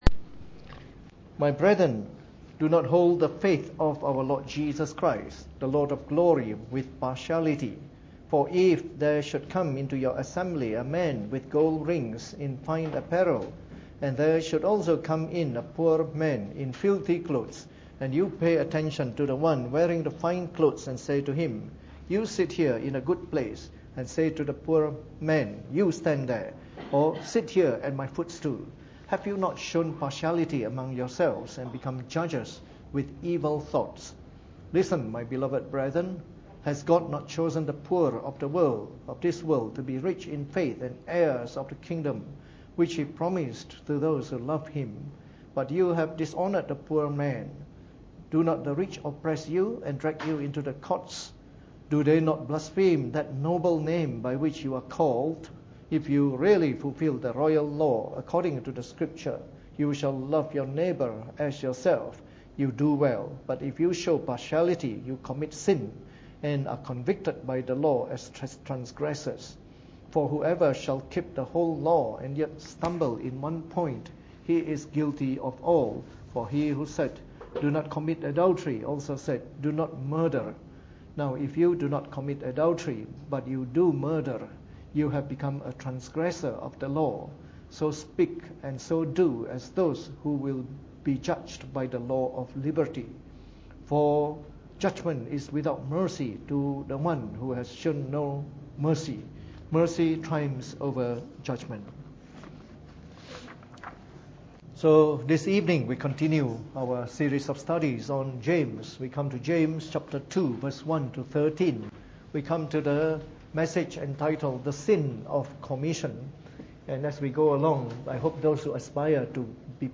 Preached on the 21st of October 2015 during the Bible Study, from our series on the Epistle of James.